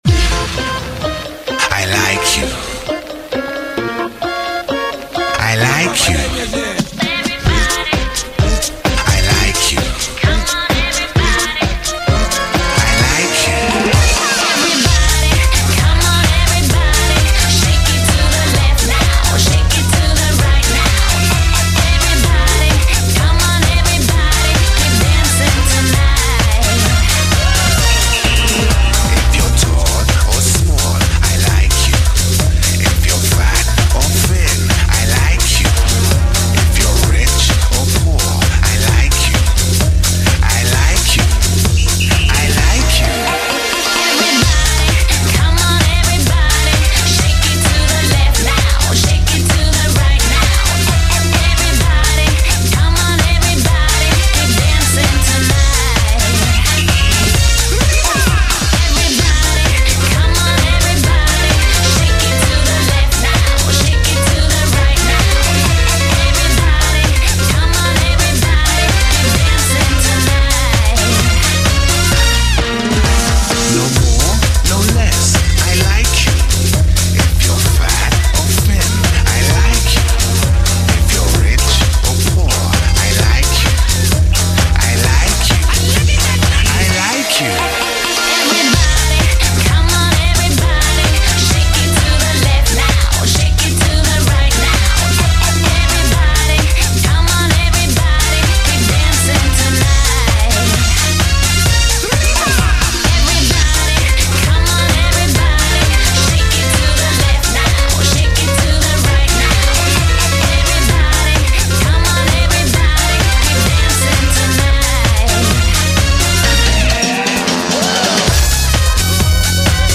Практически оригинал, голос той певицы из рекламы.
где красивая блондинка изысканным голосом исполняет песню.
Совсем танцевальный микс ))